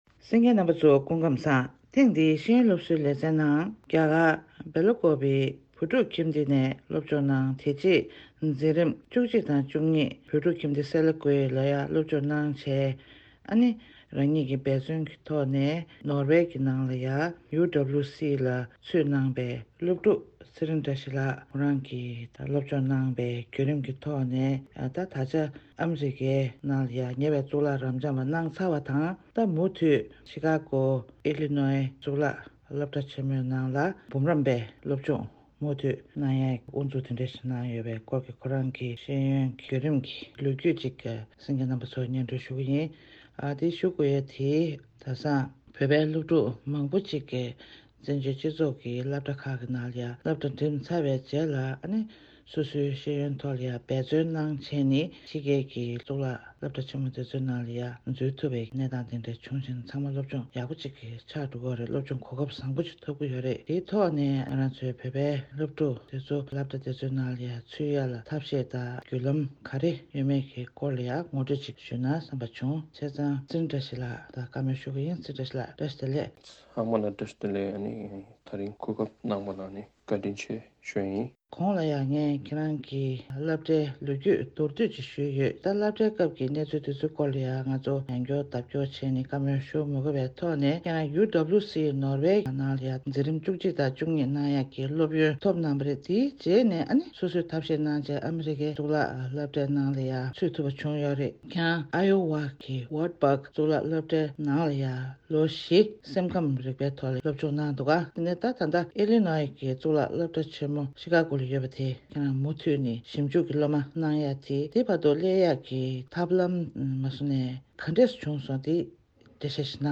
གནས་འདྲི་གནང་བ་ཞིག་གསན་གྱི་རེད།